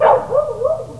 od_dogs2.wav